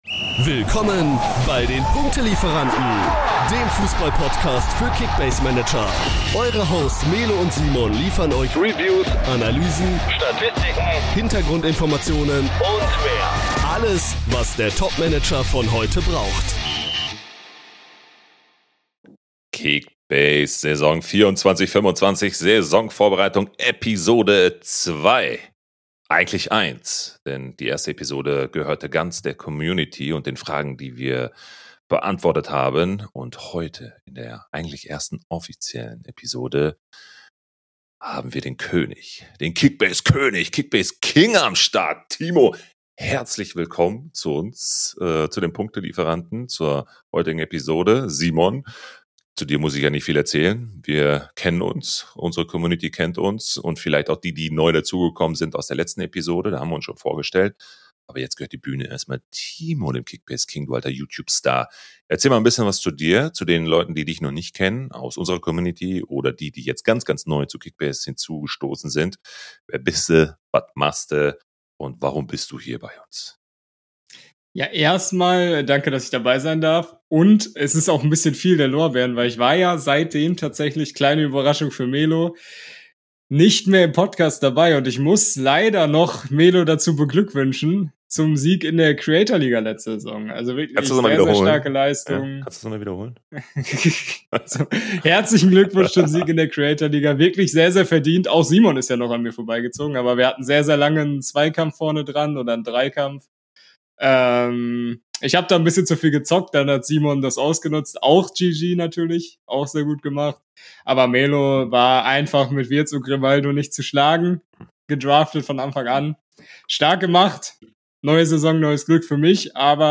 Unsere Hosts: Erlebe die perfekte Mischung aus Humor und Analyse!